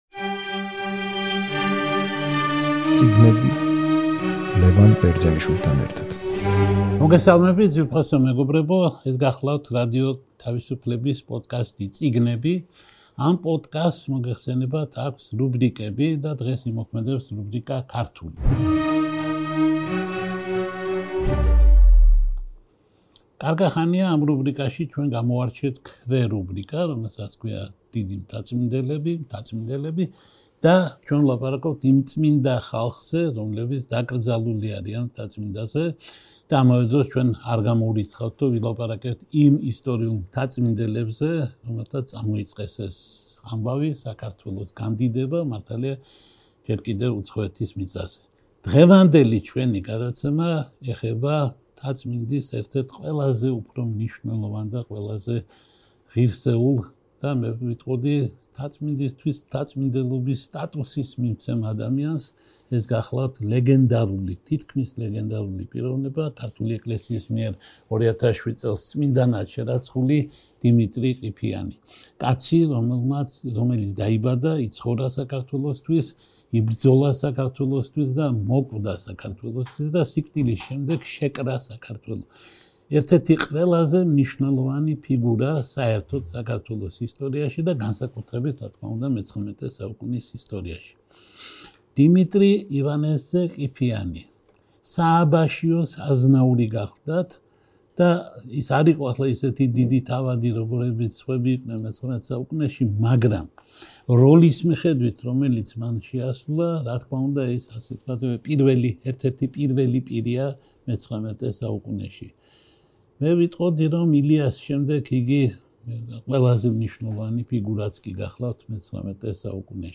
რადიო „თავისუფლება“ პოდკასტი „წიგნები“ რუბრიკით „ქართული“ და ქვერუბრიკით „მთაწმინდელები“ გთავაზობთ საუბარს XIX საუკუნის ქართველ ვარსკვლავზე, ყველა დროის ერთ-ერთ უდიდეს ქართველზე, დიმიტრი ყიფიანზე. ლევან ბერძენიშვილი, ამ პოდკასტის ავტორი, საუბრობს დიმიტრი ყიფიანზე, რომელიც იყო: სახელმწიფო და...